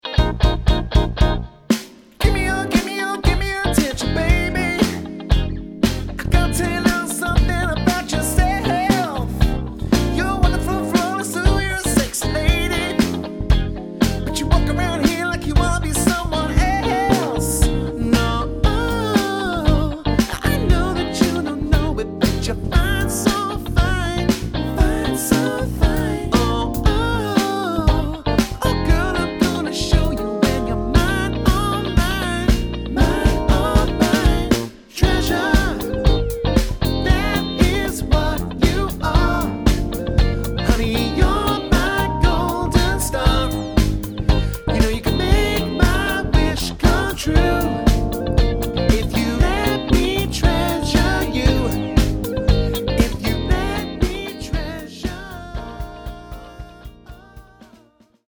bursting with vibrance, fun and energy
rich, roaring voice
guitar
keyboard
bass
drums
• Highly experienced 4-7 piece function band